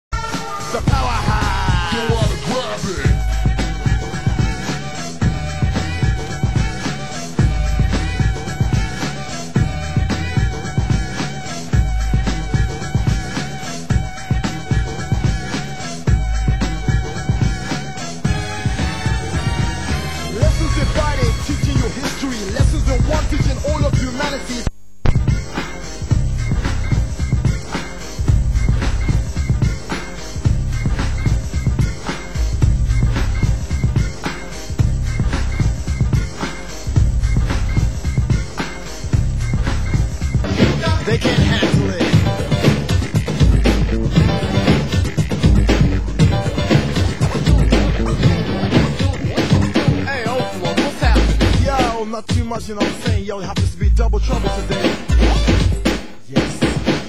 Format: Vinyl 12 Inch
Genre: Hip Hop